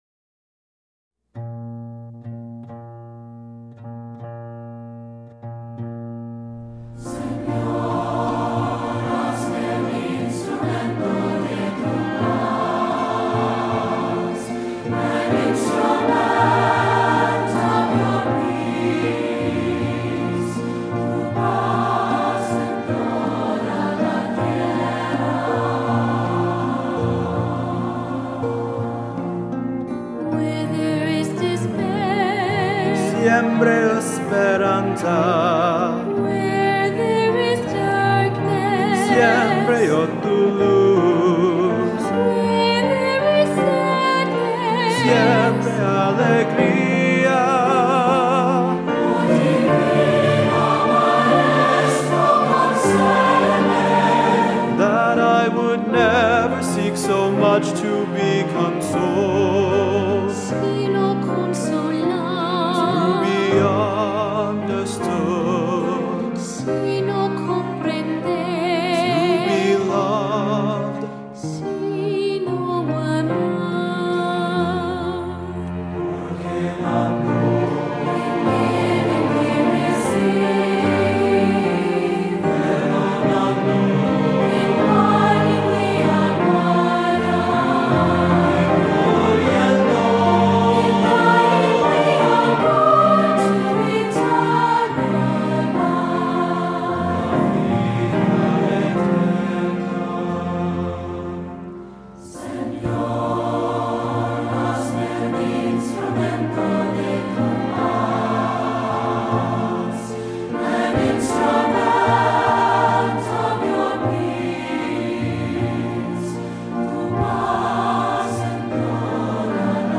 Voicing: SATB; optional Soloists; Assembly